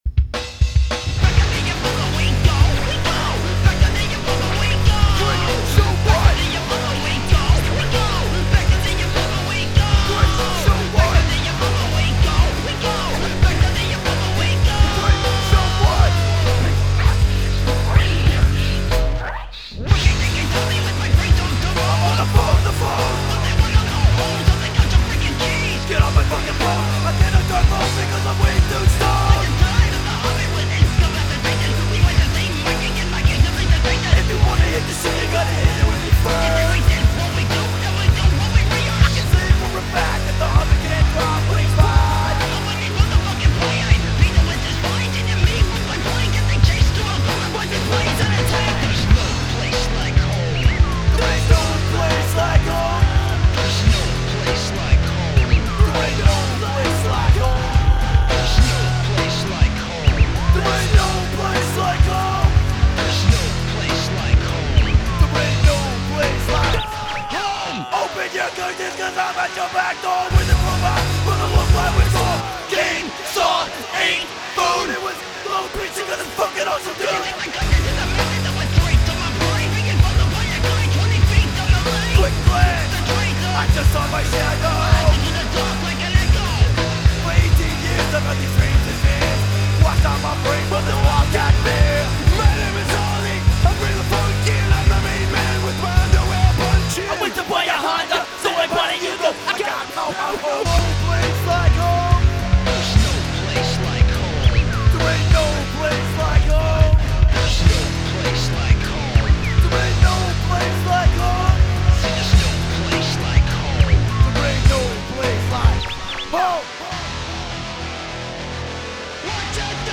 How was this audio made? (remixes, live, and unreleased tracks)